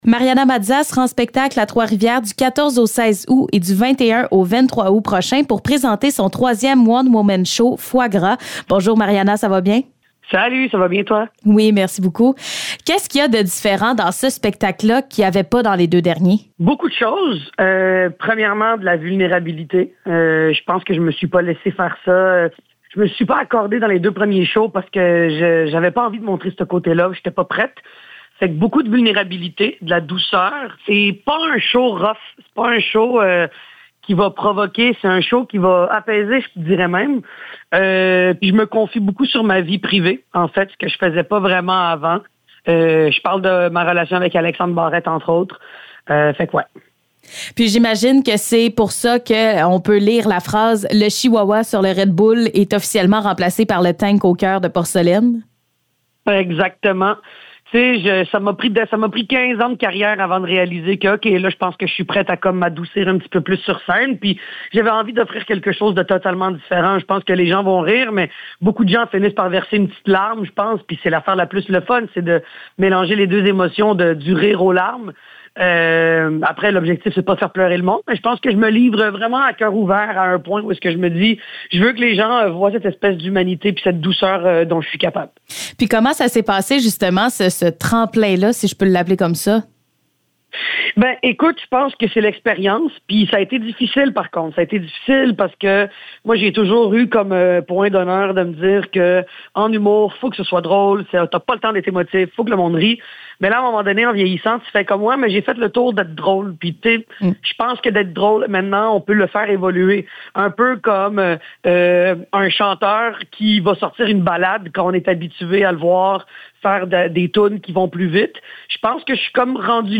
Entrevue avec Mariana Mazza